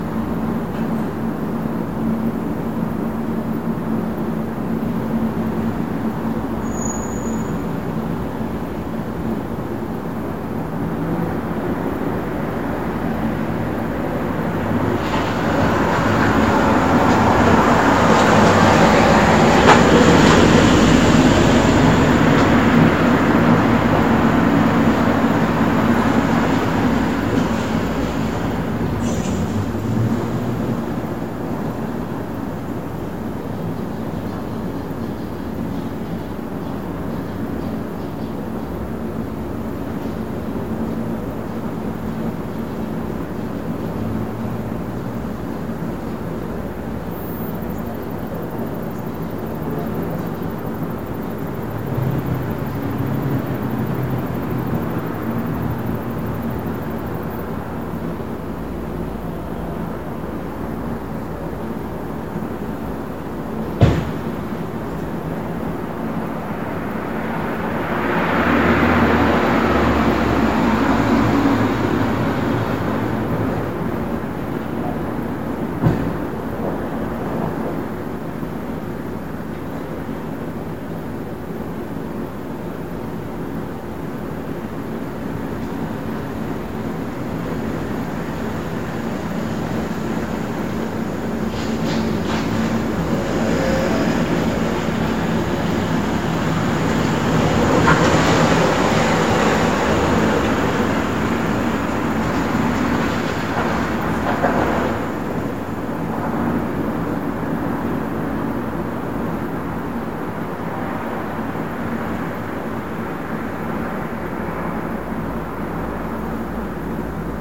伦敦街头之声 " 伦敦。午后的街头之声
描述：这是我在伦敦海德公园区酒店房间的三楼阳台上录制的录音。这是一个嘈杂的地方，但非常大气，非常适合录制街道声音，尤其是立体声。记录在Tascam DR40上。
标签： 氛围 环境 伦敦 气氛 晚上 夜间 现场记录 英格兰 大气 城市
声道立体声